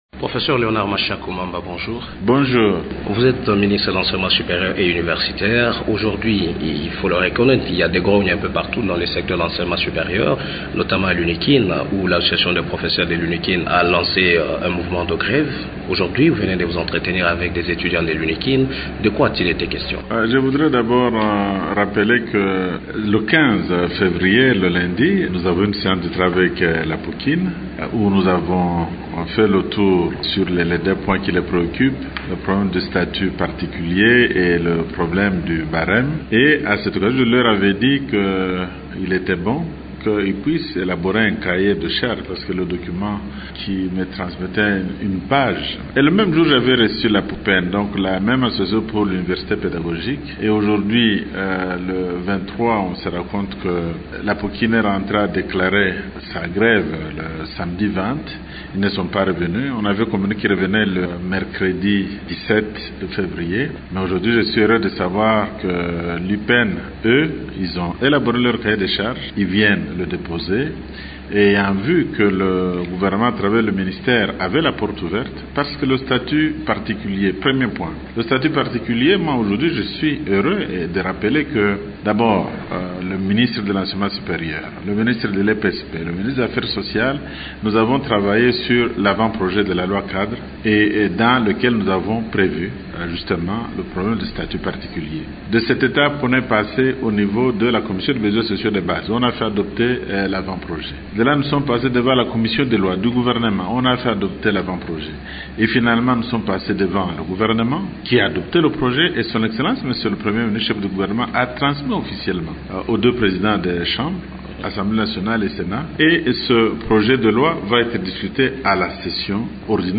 Nous parlons de cette grève de l’Unikin avec notre invité du jour, le professeur Léonard Mashako Mamba, ministre de l’enseignement supérieur et universitaire.